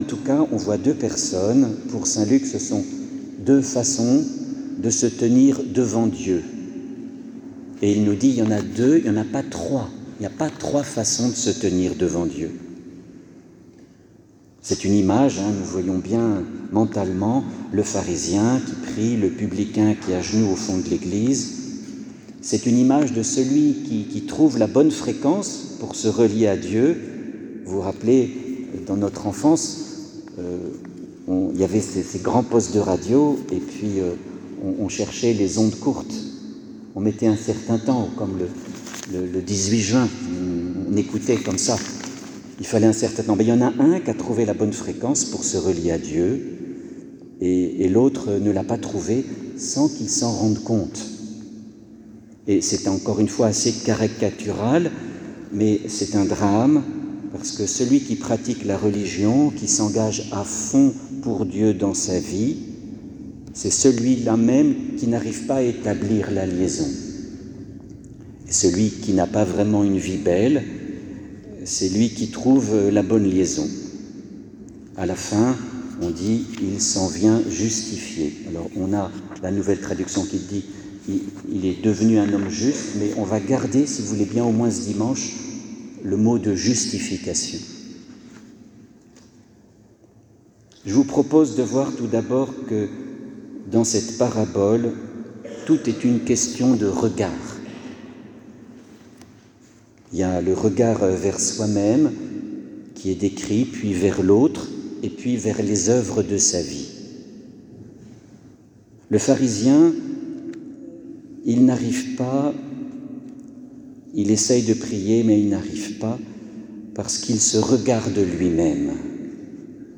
HOMELIE Le pharisien et le publicain